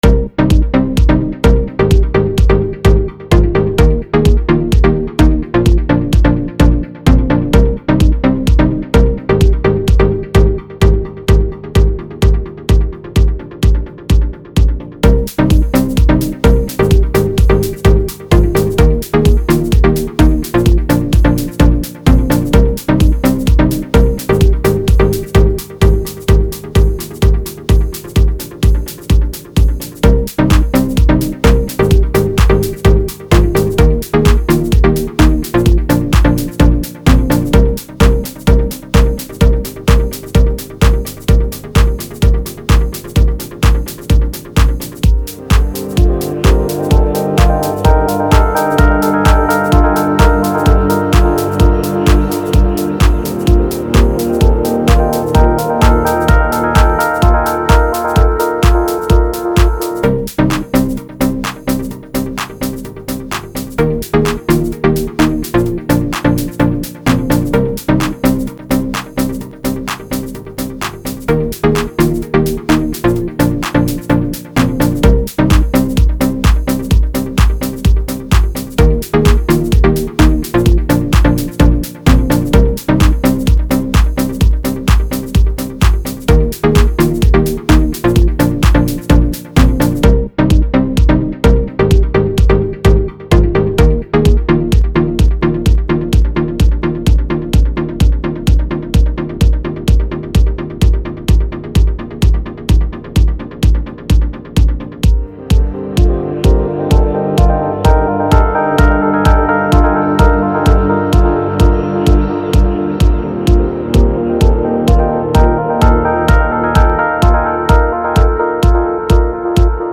house track mit analogsynth-thema und house drums